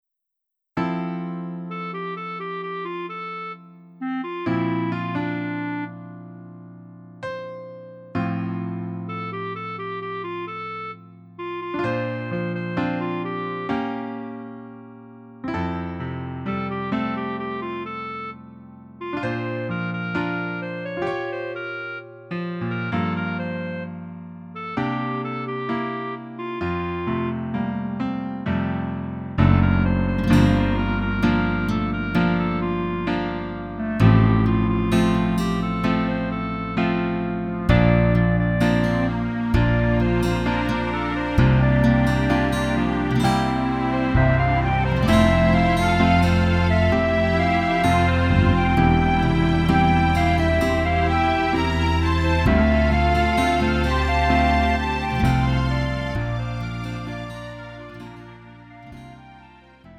음정 -1키 3:32
장르 가요 구분 Lite MR
Lite MR은 저렴한 가격에 간단한 연습이나 취미용으로 활용할 수 있는 가벼운 반주입니다.